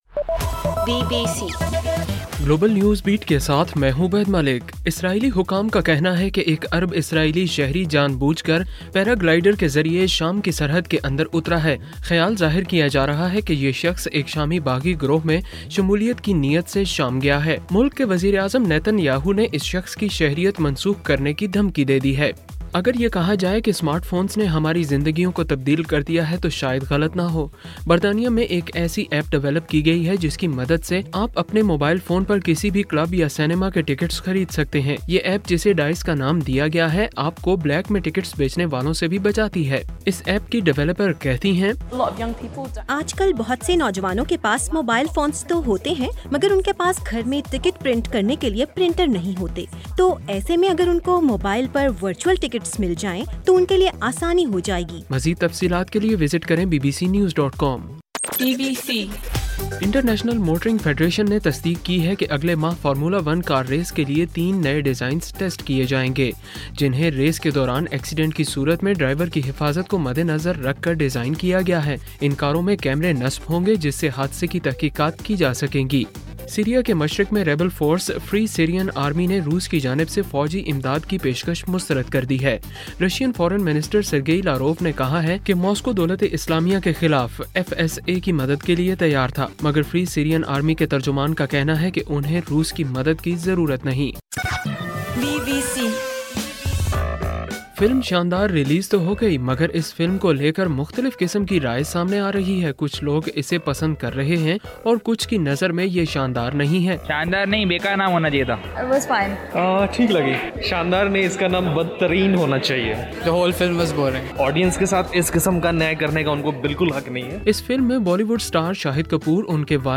اکتوبر 25: رات 9 بجے کا گلوبل نیوز بیٹ بُلیٹن